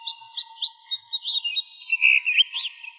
现场记录 4 " 鸟类！"。清洁
描述：记录的鸟被编辑为更清洁
Tag: 早晨 鸣叫